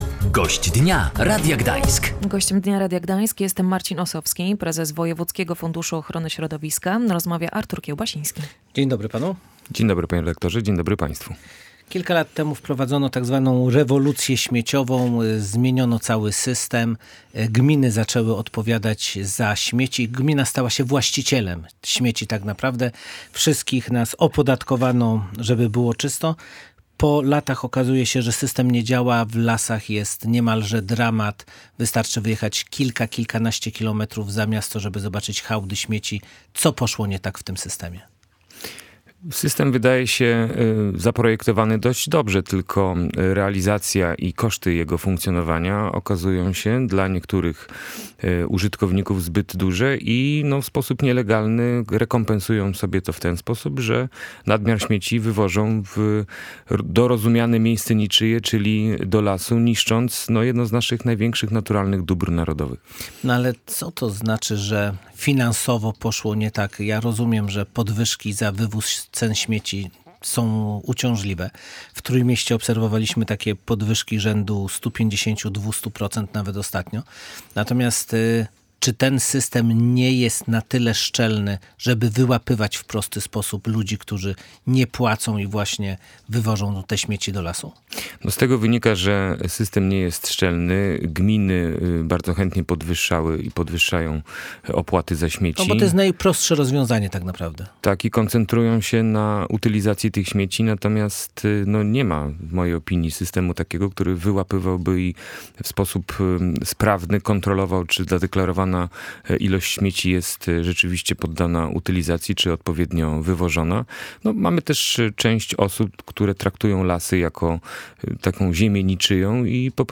Tysiąc wagonów śmieci co roku wywożonych jest z lasów. Wiele osób wciąż unika płacenia za odpady w gminach i pozbywa się ich właśnie w tam – mówił w Radiu Gdańsk Marcin Osowski, prezes Wojewódzkiego Funduszu Ochrony Środowiska. Jego zdaniem system nie jest szczelny i trzeba to zmienić.